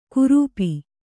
♪ kurūpi